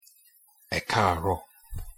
pronunciation_yo_ekaaro.mp3